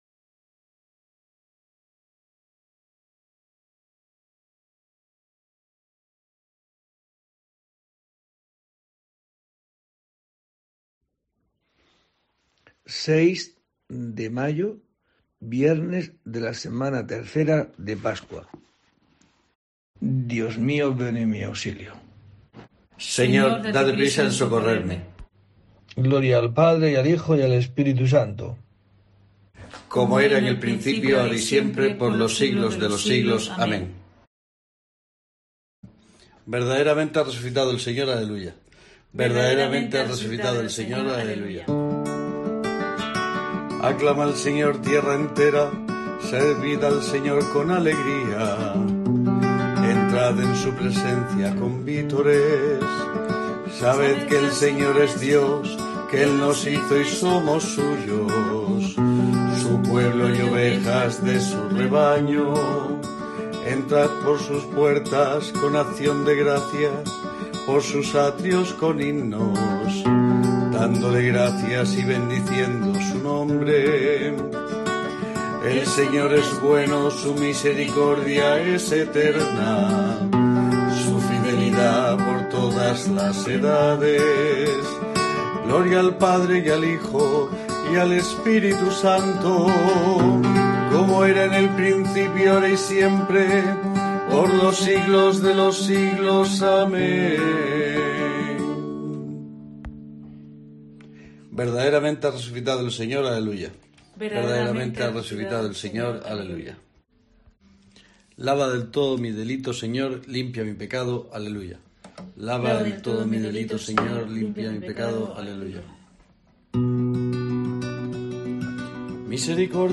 06 de mayo: COPE te trae el rezo diario de los Laudes para acompañarte